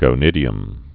(gō-nĭdē-əm)